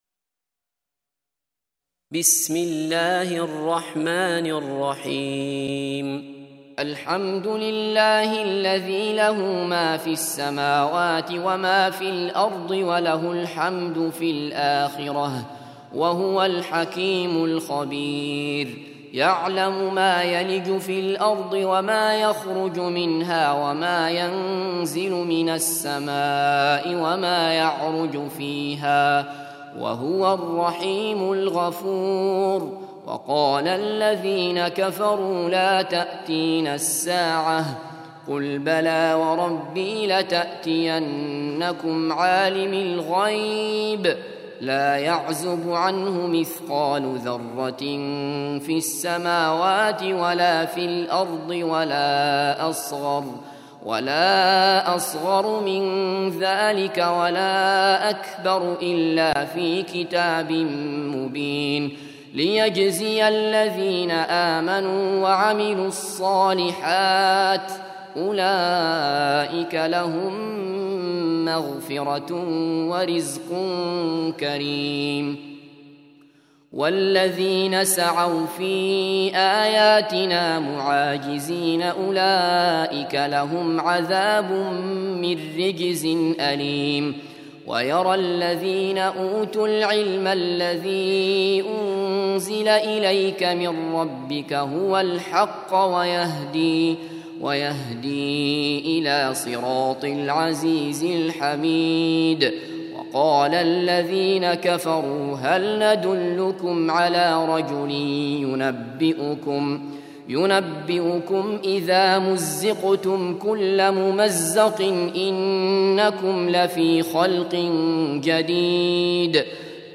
34. Surah Saba' سورة سبأ Audio Quran Tarteel Recitation
Surah Sequence تتابع السورة Download Surah حمّل السورة Reciting Murattalah Audio for 34.